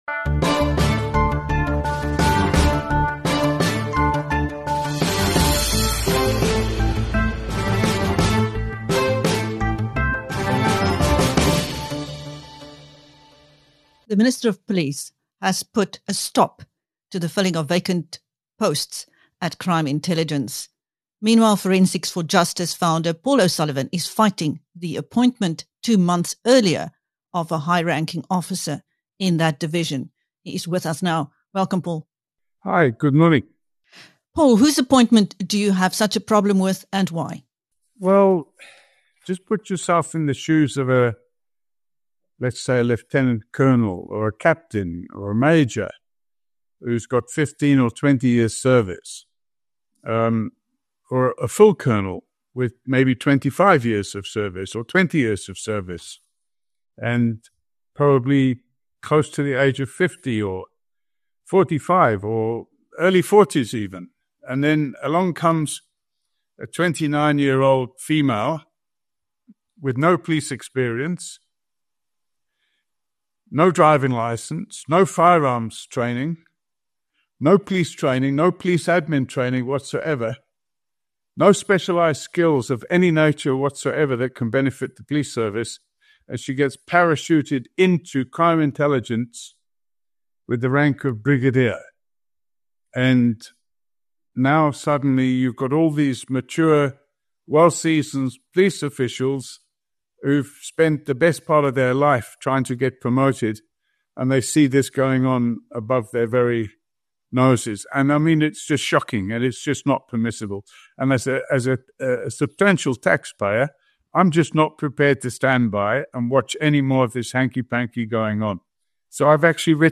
Welcome to BizNews Radio where we interview top thought leaders and business people from South Africa and across the globe.